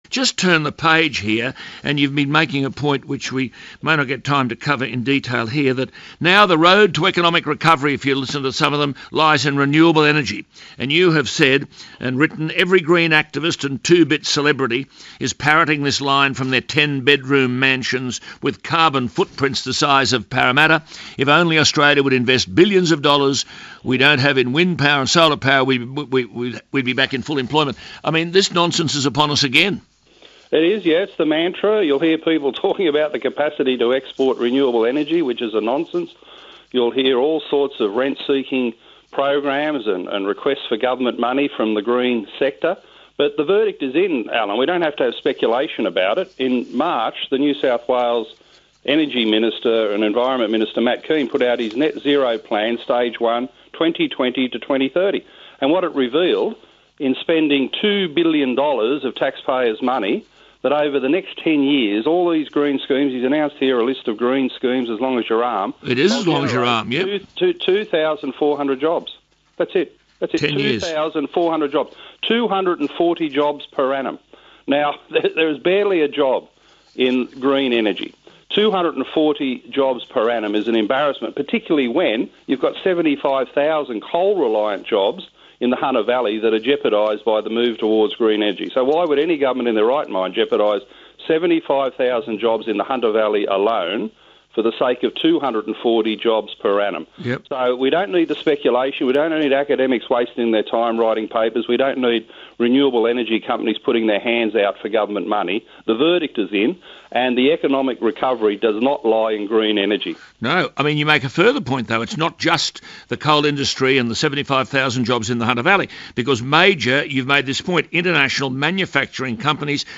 Here he is being interviewed by 2GB’s Alan Jones and one of Alan’s last broadcasts for that radio station, as he hangs up his gloves and heads for retirement.